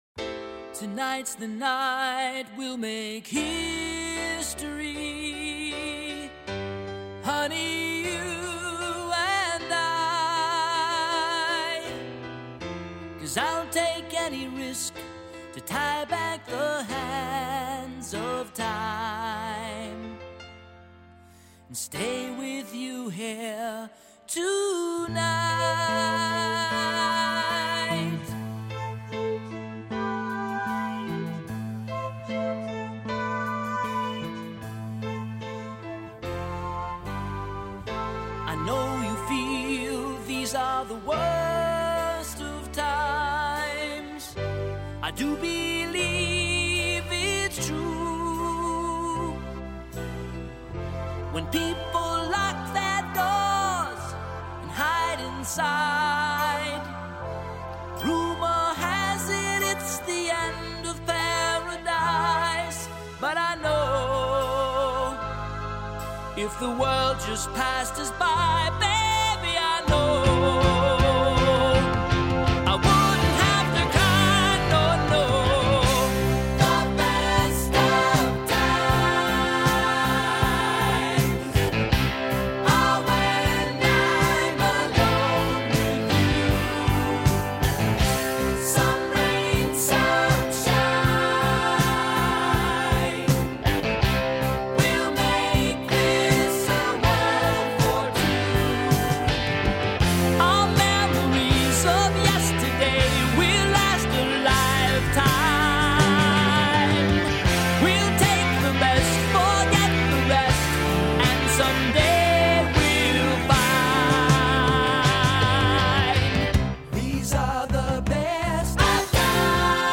These are some of our favorite slow dance songs.